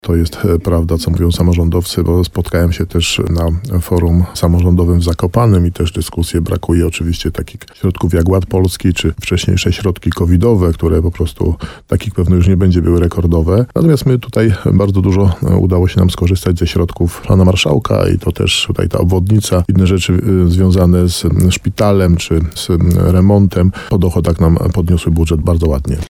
Projekt uchwały zakłada, że w 2026 roku wyniesie on ponad 342 miliony złotych. Mimo, że wiele samorządów narzeka na niskie wpływy z dotacji zewnętrznych, to tak nie jest w tym przypadku – podkreślał starosta nowosądecki Tadeusz Zaremba w programie Słowo za Słowo na antenie RDN Nowy Sącz.